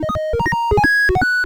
retro_beeps_collect_item_07.wav